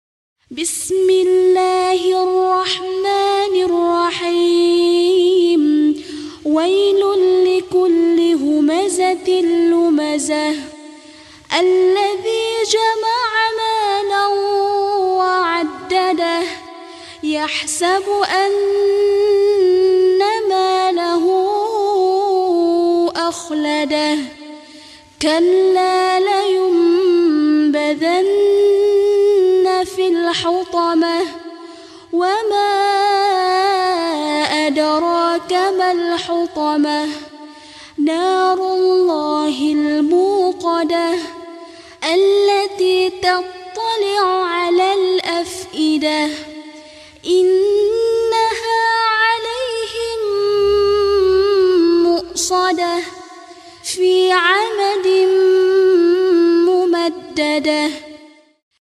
Murottal Juz Amma